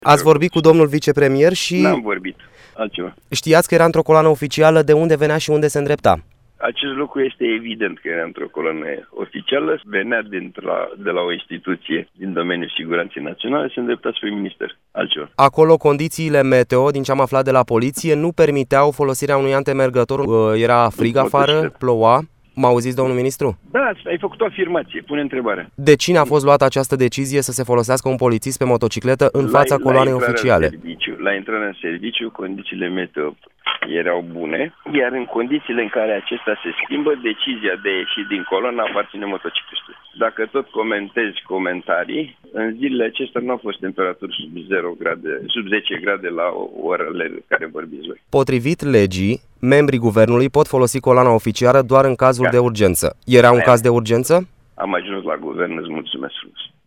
Anul trecut, Europa FM l-a sunat pe deputat